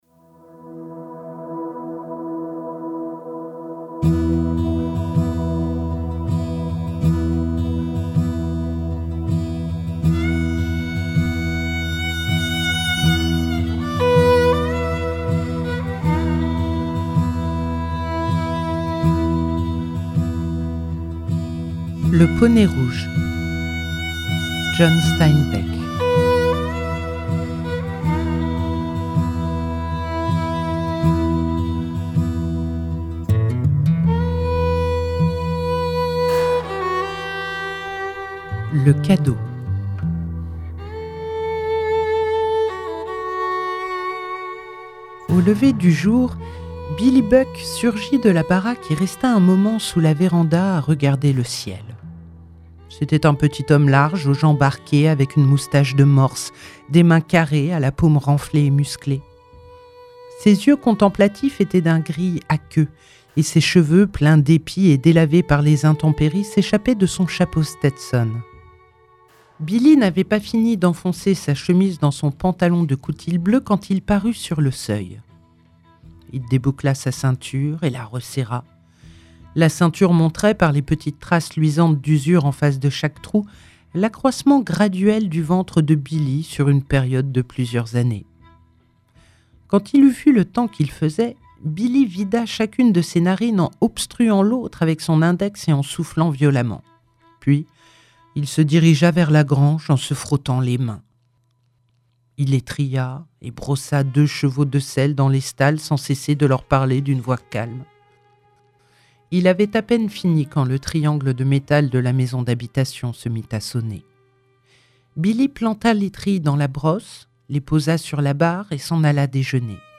🎧 Le poney rouge - John Steinbeck - Radiobook